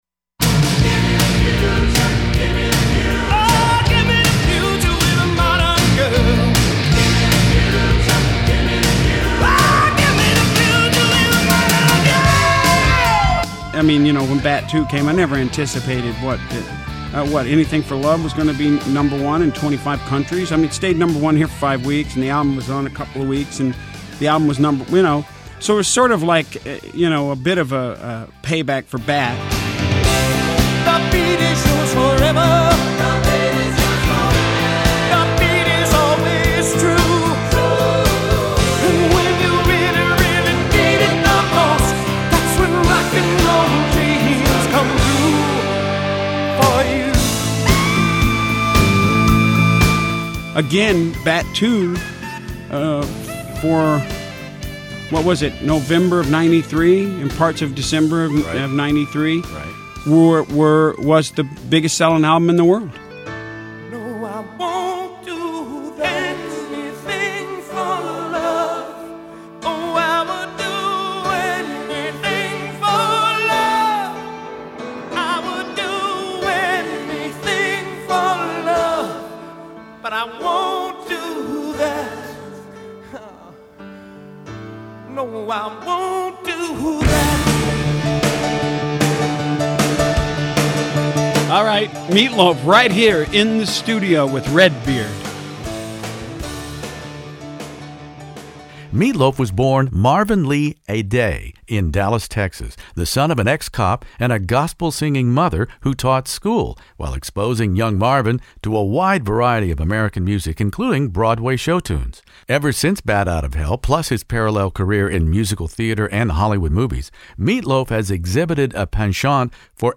Here is my rare colorful classic rock conversation with the man who the New York Times respectfully refered to as “Mr. Loaf”, in an attempt to get our arms around his most unorthodox career, from North Texas football lineman to journeyman actor to international singer who hit the stage like a Bat Out of Hell .